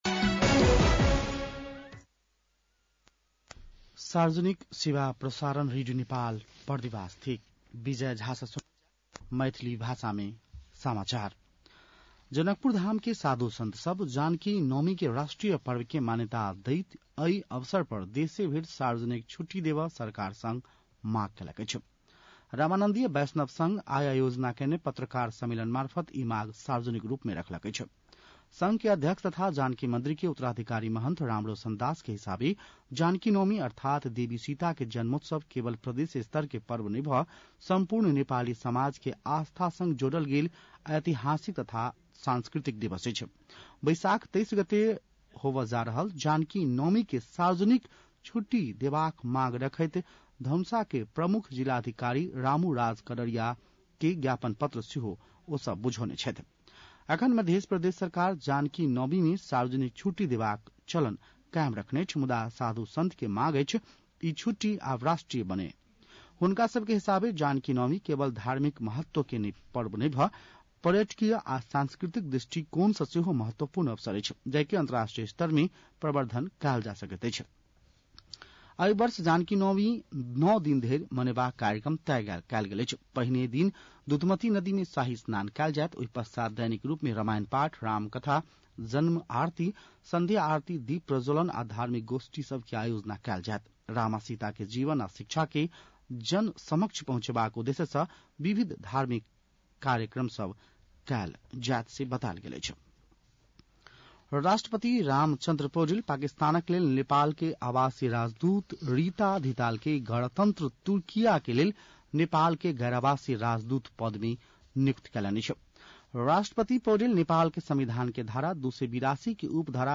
मैथिली भाषामा समाचार : ७ वैशाख , २०८२
6-pm-maithali-news.mp3